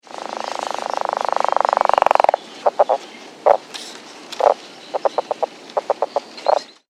Sound This is a recording of the advertisement calls of a Northern Leopard Frog
recorded during daylight in Kittitas county, Washington.